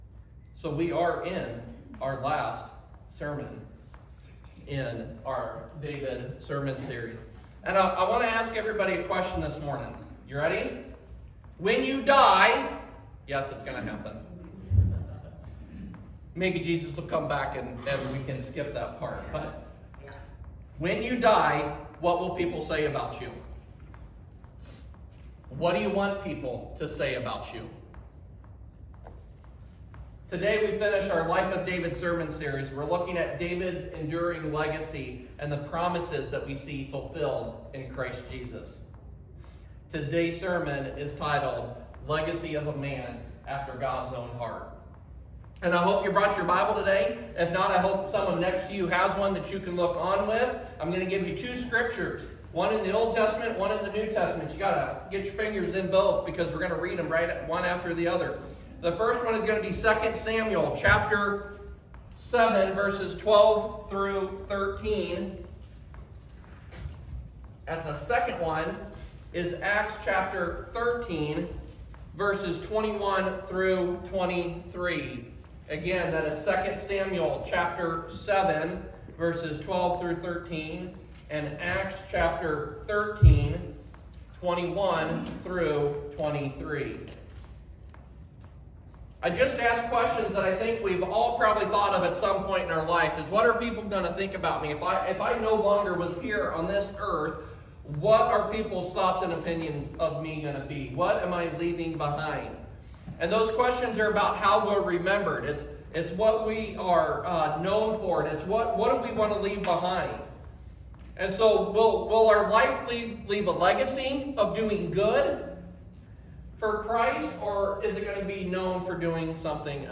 Sermons | Engage Church